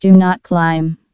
TCAS voice sound samples. ... Artificial female voice.
do_not_climb.wav